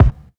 shrt_brk_kick.wav